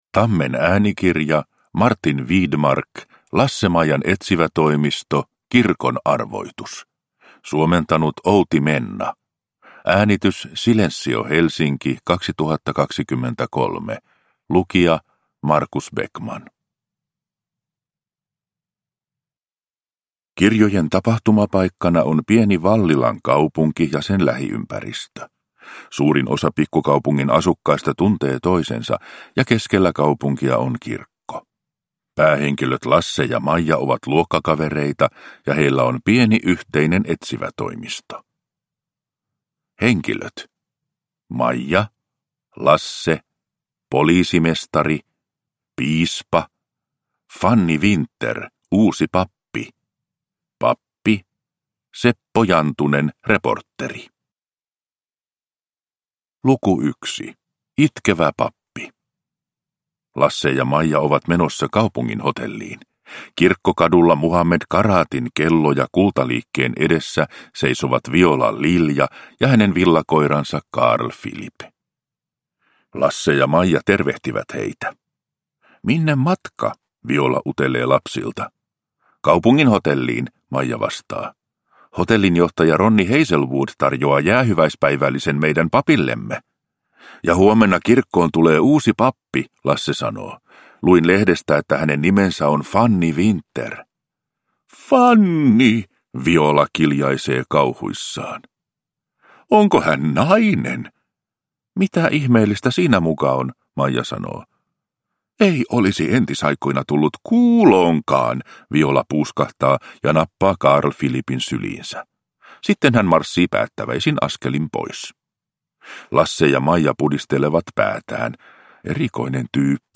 Kirkon arvoitus. Lasse-Maijan etsivätoimisto – Ljudbok